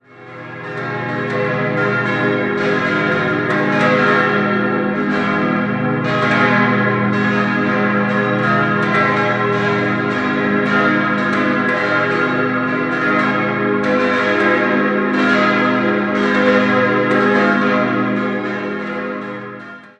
Weil diese bald schon zu klein wurde, entstand im Jahr 1935 das heutige Gotteshaus. 5-stimmiges Geläut: as°-c'-es'-f'-as' Die Glocken wurden im Jahr 1935 von der Gießerei Rüetschi in Aarau gegossen.